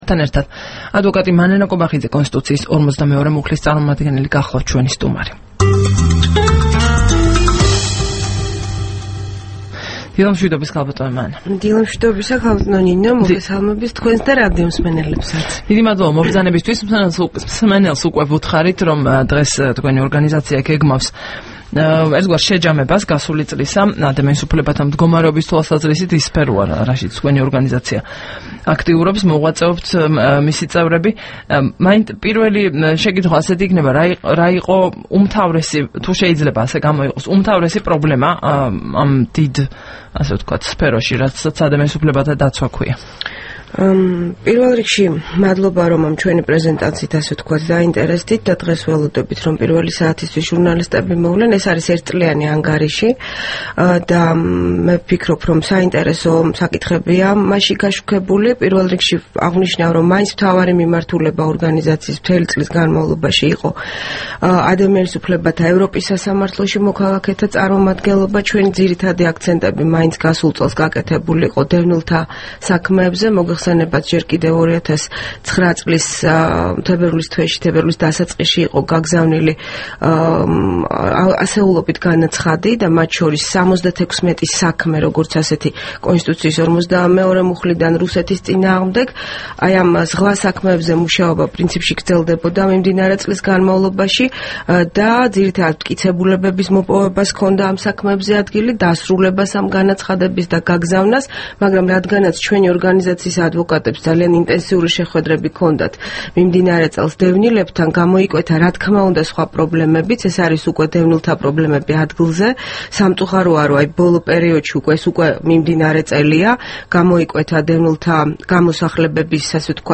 საუბარი მანანა კობახიძესთან